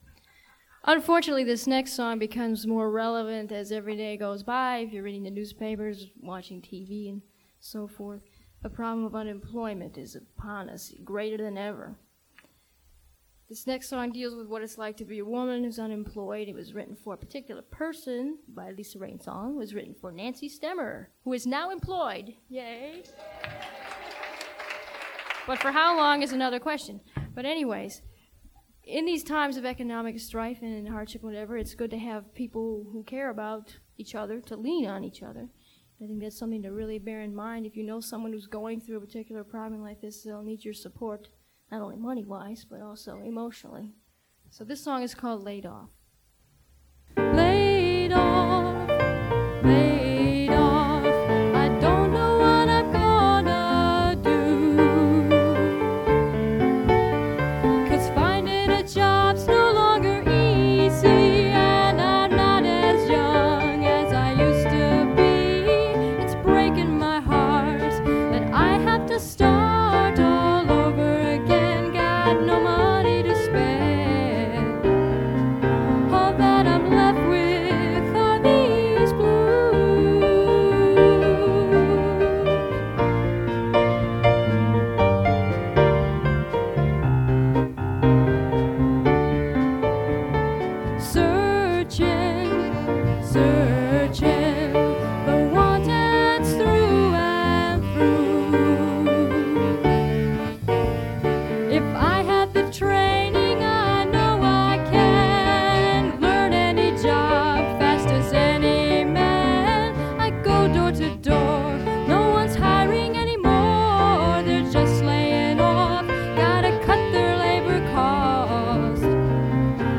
singing and playing bass
keyboard
and often singing harmony or sharing the lead vocal.
Unfortunately, some of the recordings are not the greatest in terms of quality
Laid Off – performed live with Sister Rhapsody in 1980.